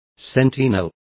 Complete with pronunciation of the translation of sentinel.